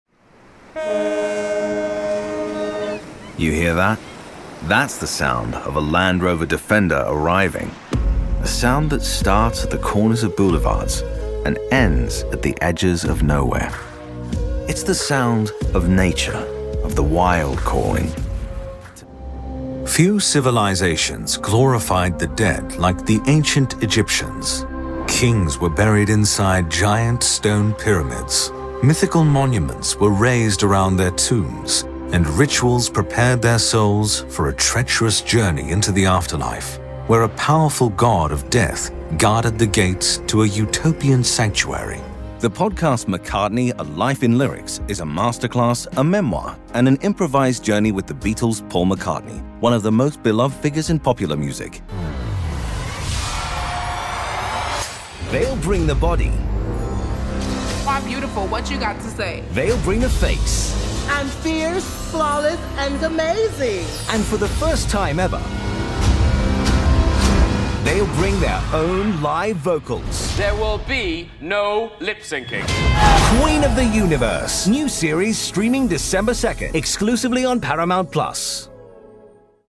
Commercial - British
Commercial (Werbung), Station Voice, Tutorial